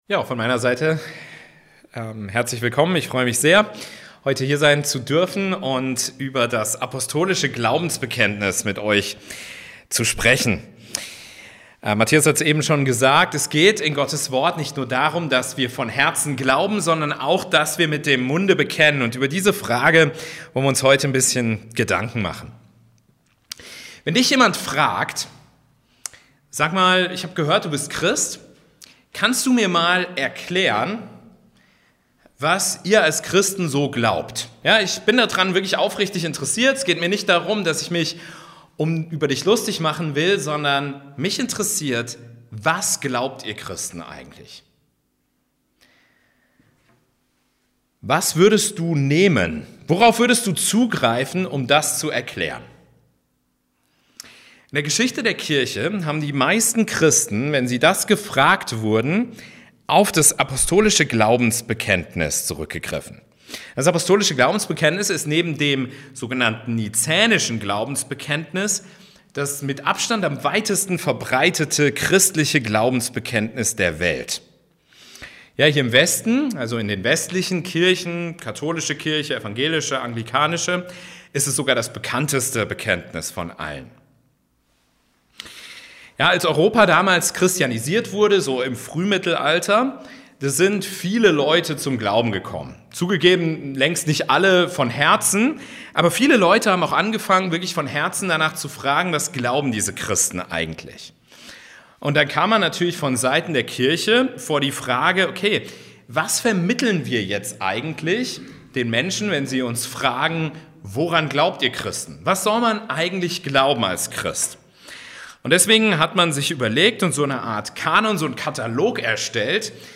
Bibelstunde